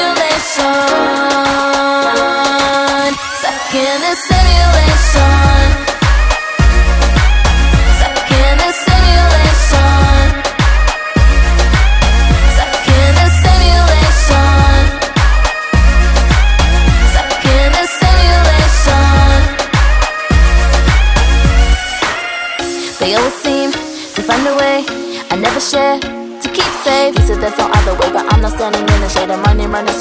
la pop, le garage anglais, le hip-hop oldschool, et la trap